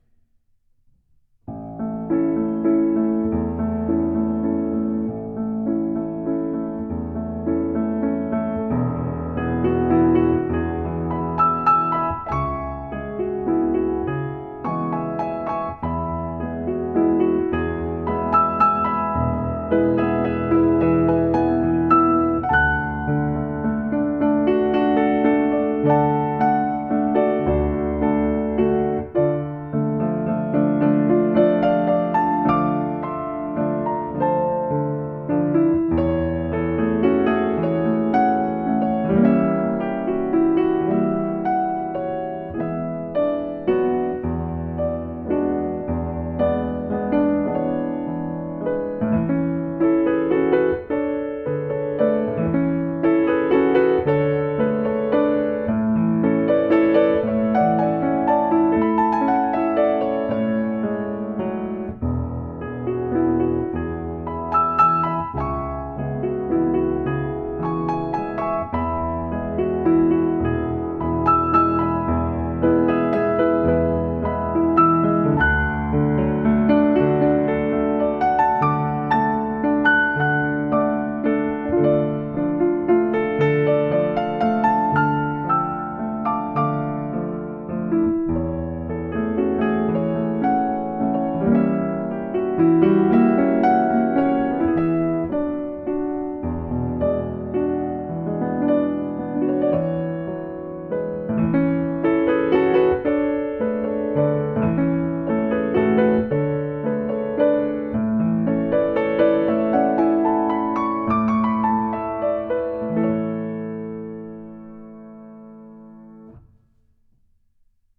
Piano accompaniment
Musical Period Romantic
Tempo 34
Rhythm Adagio 3/4 and 6/8
Meter 3/4